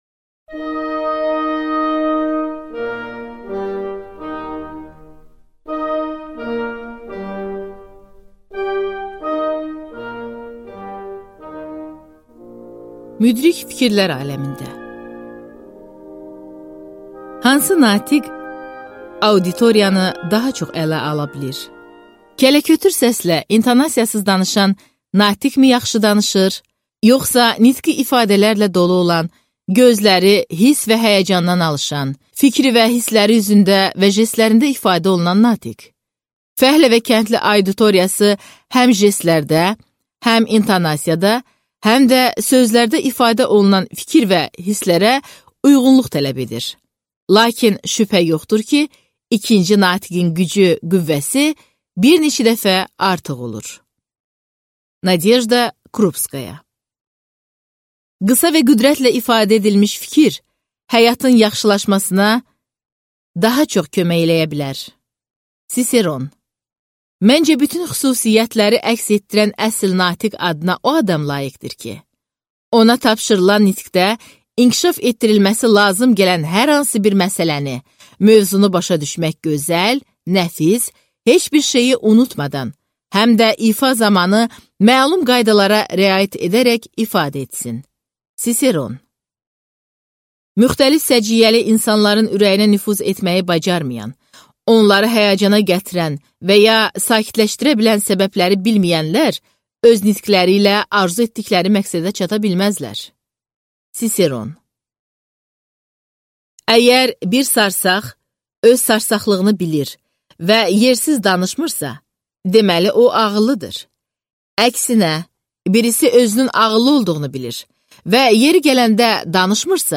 Аудиокнига Müdrik fikirlər aləmində | Библиотека аудиокниг